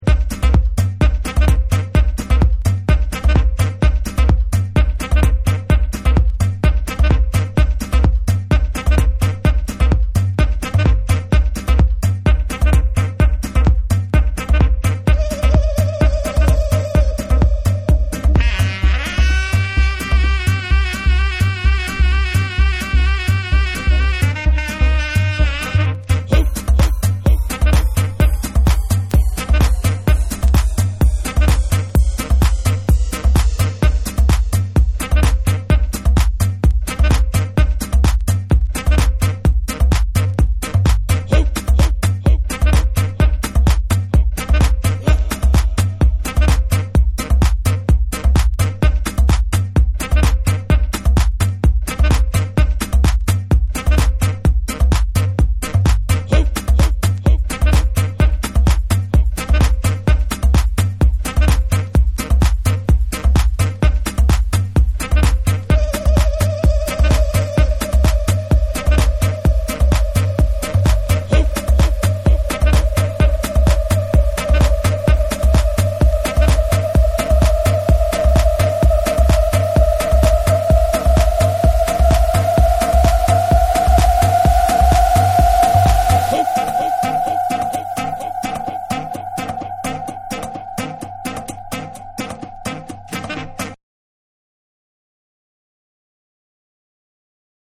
バルカン・ミニマル（SAMPLE 3）がオススメ！
TECHNO & HOUSE